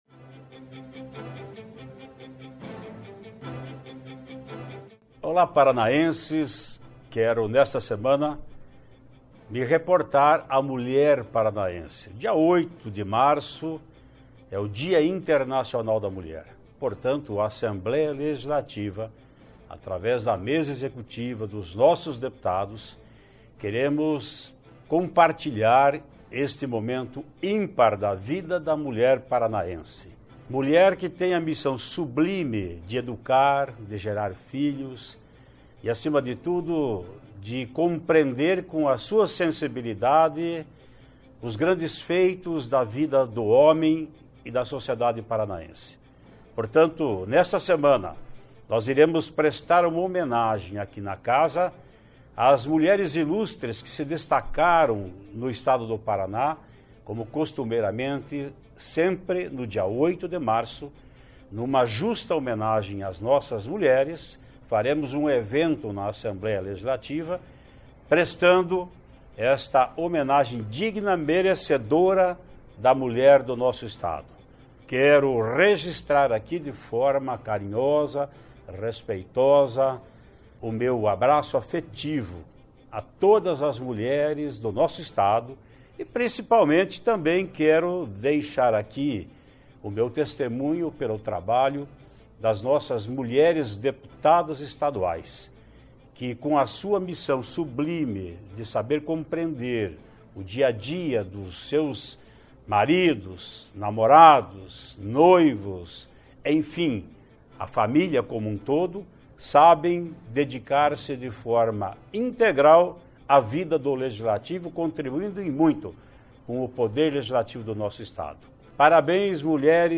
No Palavra do Presidente desta semana o deputado Ademar Traiano presta, em nome da Assembleia Legisaltiva, uma homenagem a todas as paranaenses pelo Dia Internacional da Mulher, no 8 de março.// Confira: